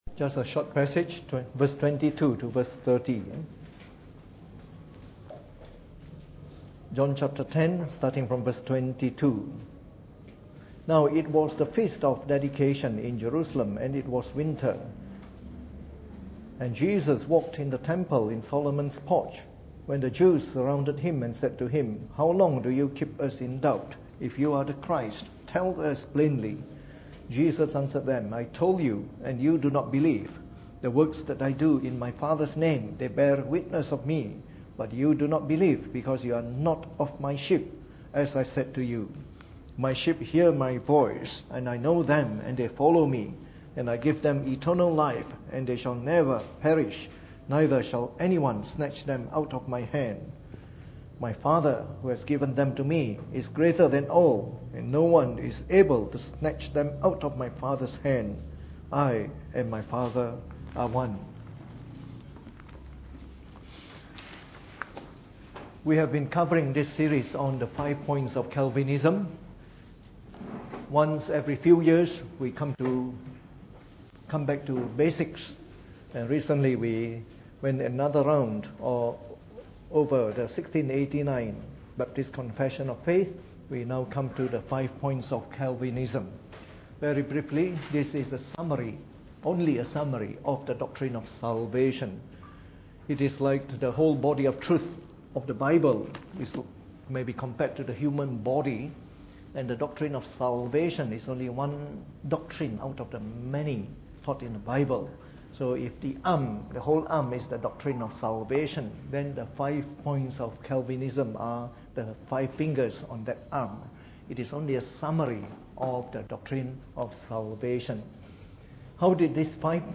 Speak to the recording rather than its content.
Preached on the 28th of September 2016 during the Bible Study, from our series on the Five Points of Calvinism.